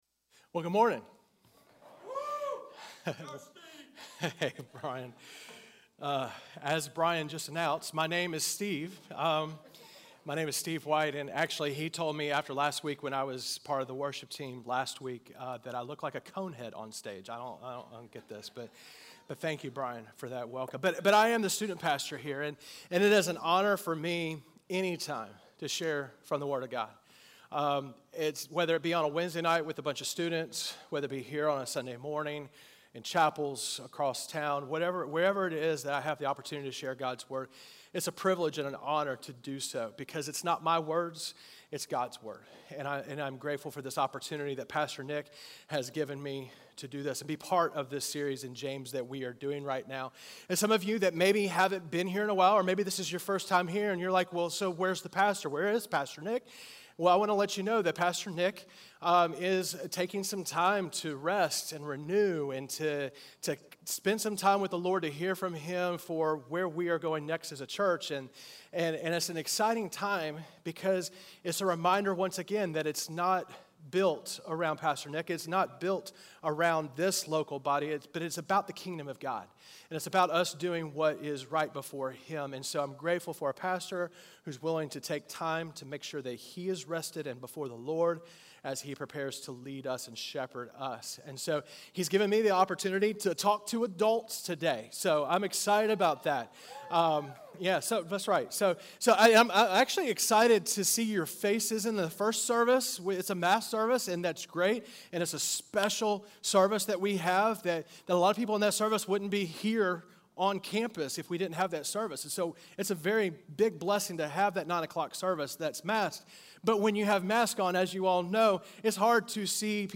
A message from the series "Faith Awakened."